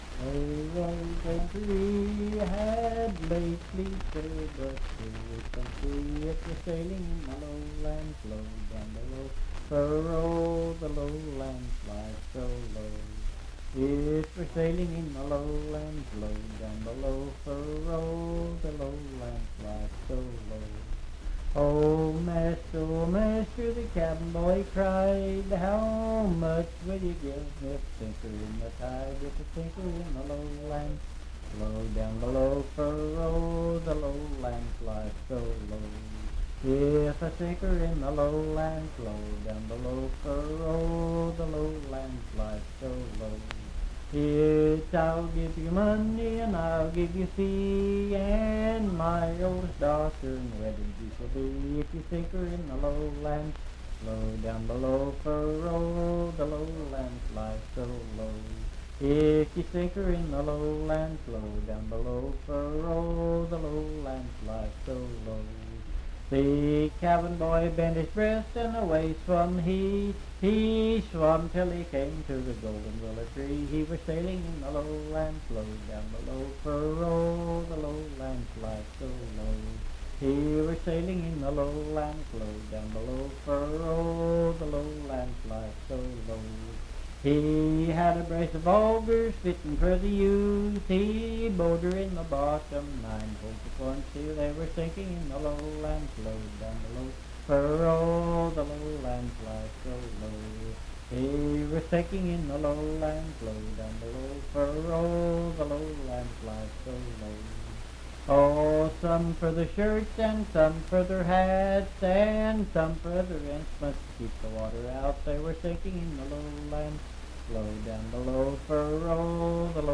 Unaccompanied vocal music
Verse-refrain 9(6).
Voice (sung)
Pendleton County (W. Va.)